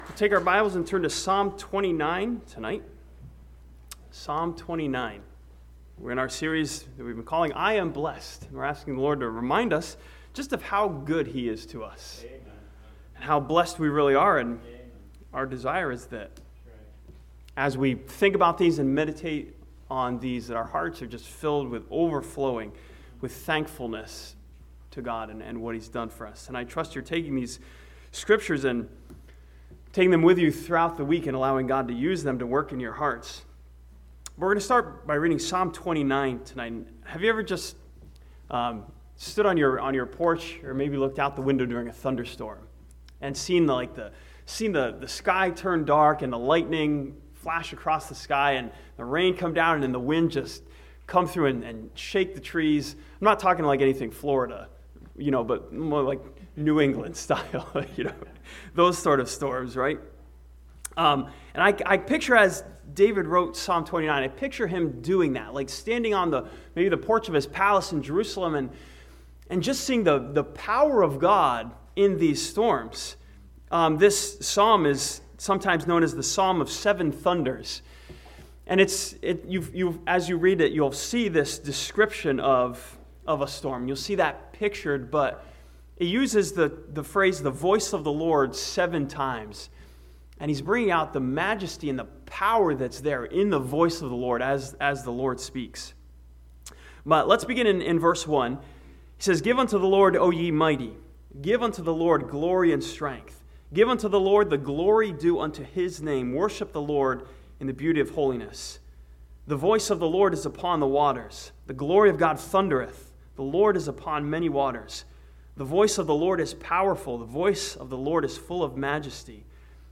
This sermon from Psalm 29 studies the fact that believers have been blessed with peace through Jesus Christ.